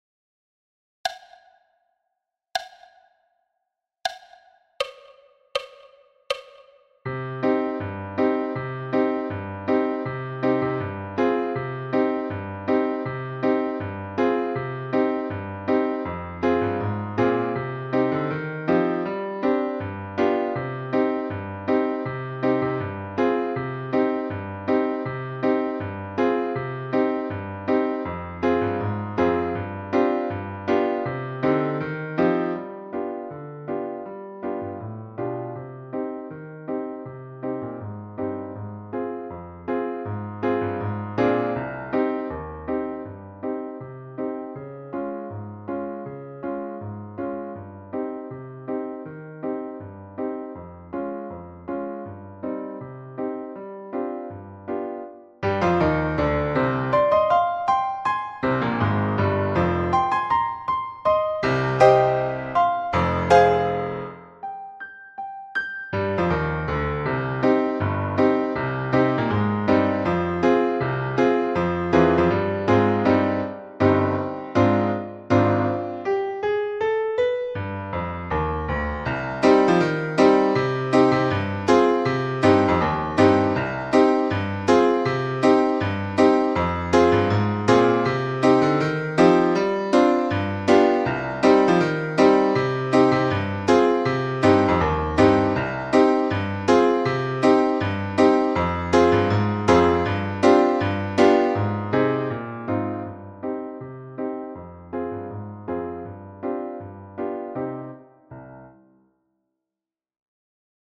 Midi pile – piano à 80 bpm-Part
Midi-pile-piano-a-80-bpm-Part.mp3